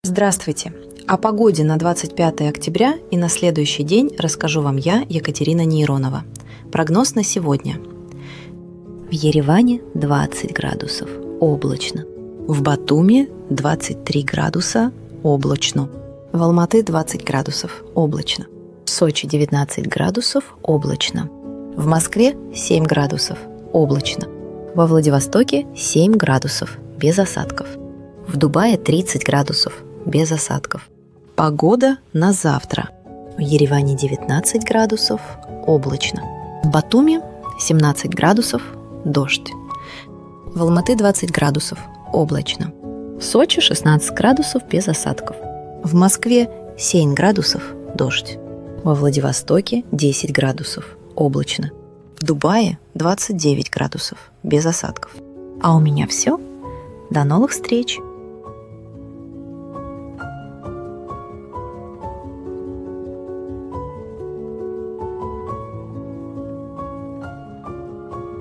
• TTS-сервисы - ElevenLabs, SaluteSpeech, локальный Piper TTS
В итоге для прогноза погоды “сшиваются” вместе: интро (заданное пользователем), прогноз по каждому городу на сегодня и завтра, и аутро.
weather.mp3